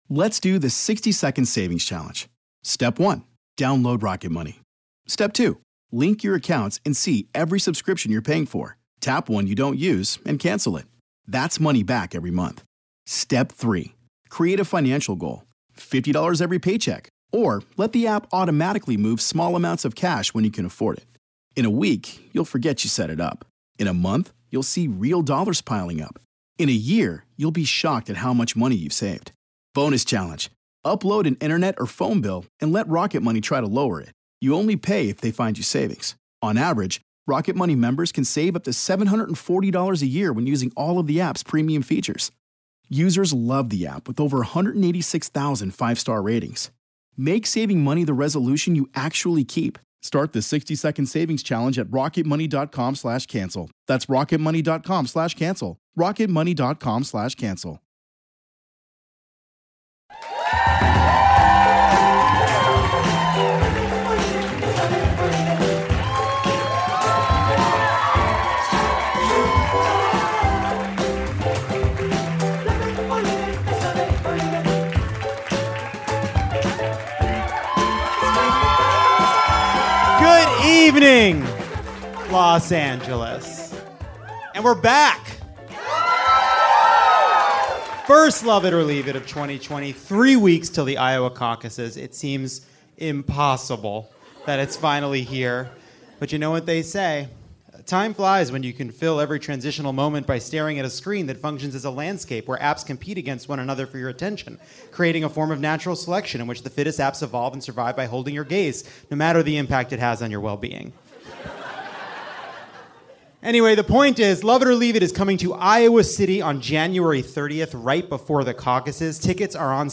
Former Deputy National Security Advisor Ben Rhodes joins to unpack the latest on Iran and the congressional response. Plus comedians Hari Kondabolu and Alice Wetterlund join to look at the fight over the coming impeachment trial, high school reunions, crowded gyms in January, and more. 2020 is here.